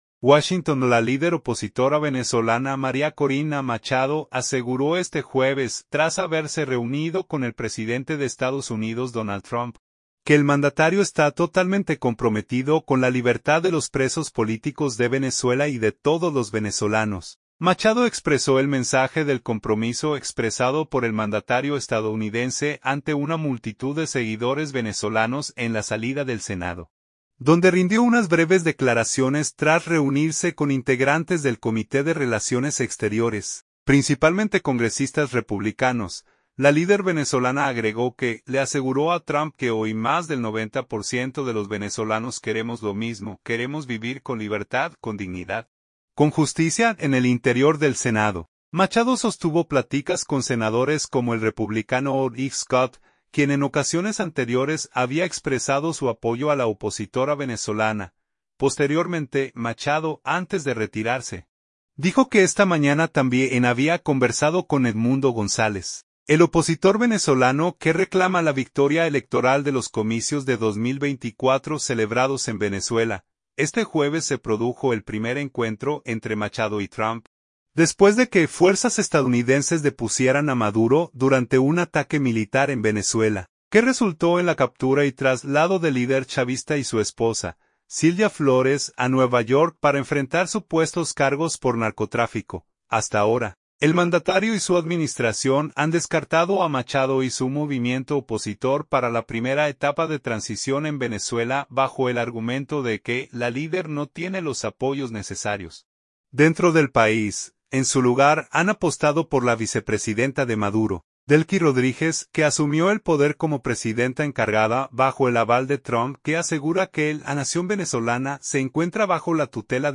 Machado expresó el mensaje del compromiso expresado por el mandatario estadounidense ante una multitud de seguidores venezolanos en la salida del Senado, donde rindió unas breves declaraciones tras reunirse con integrantes del comité de Relaciones Exteriores, principalmente congresistas republicanos.